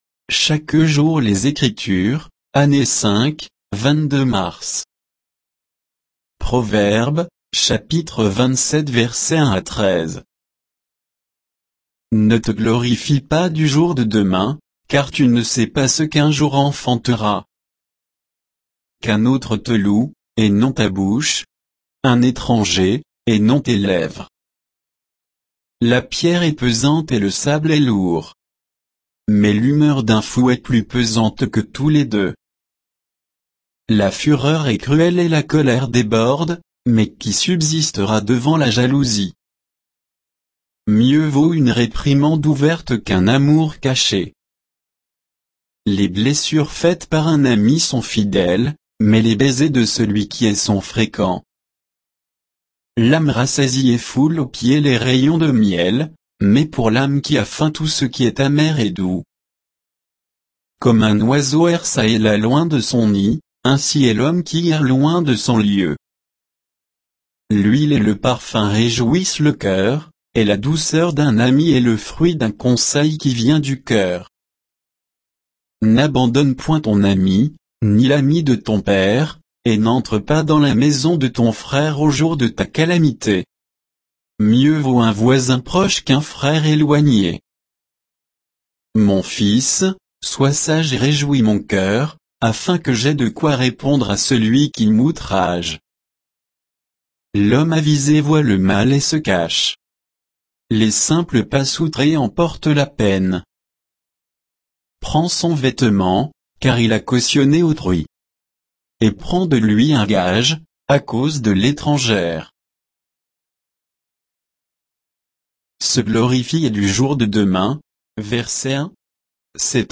Méditation quoditienne de Chaque jour les Écritures sur Proverbes 27